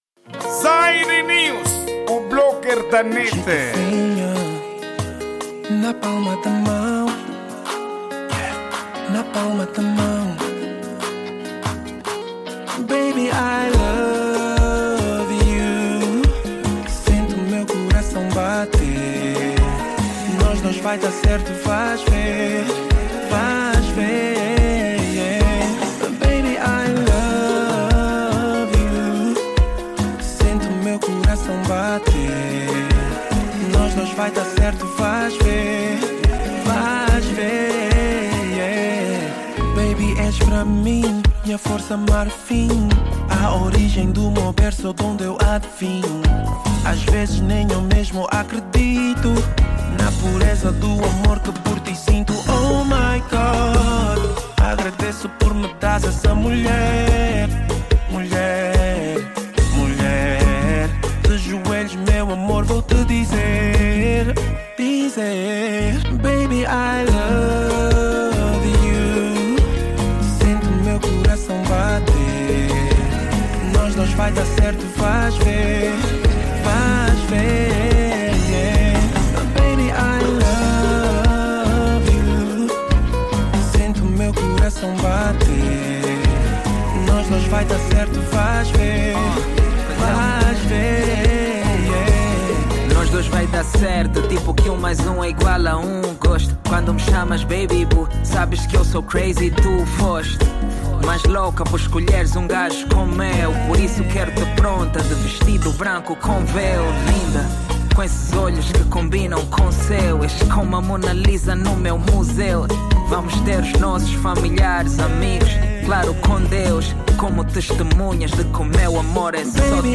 Género:Zouk